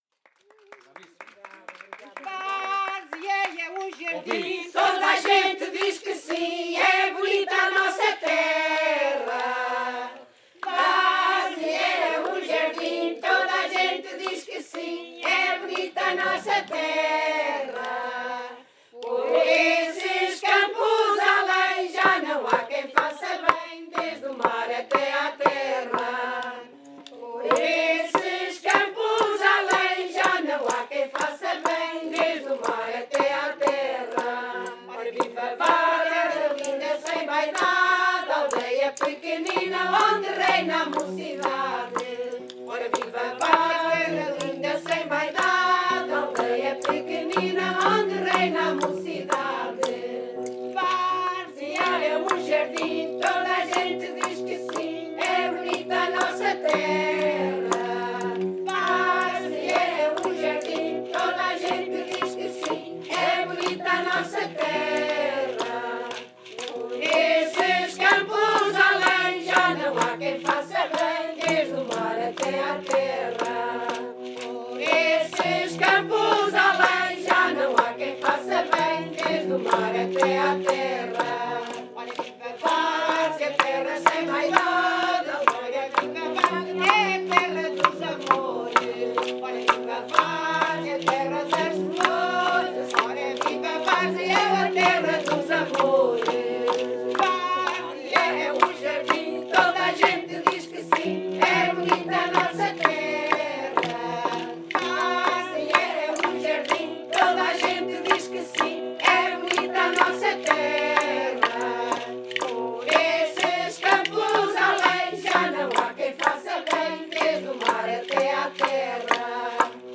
Grupo Etnográfico de Trajes e Cantares do Linho de Várzea de Calde
Tipo de Registo: Som Local: Várzea de Calde